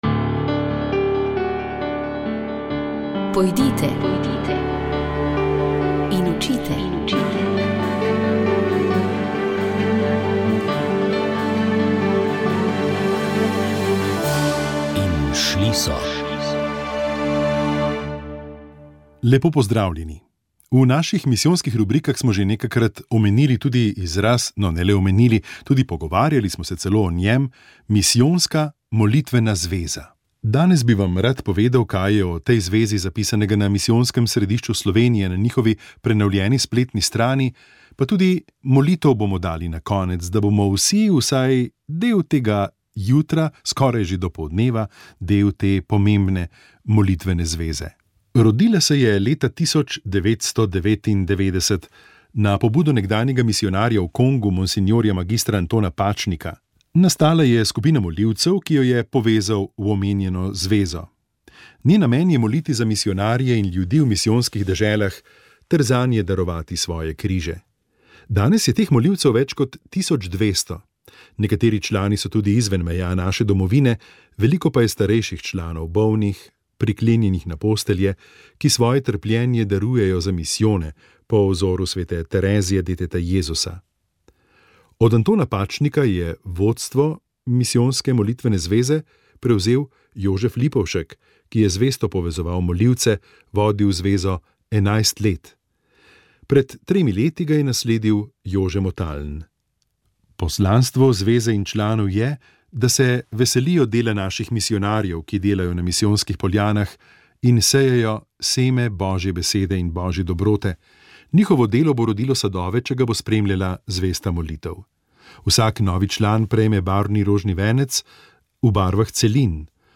v studiu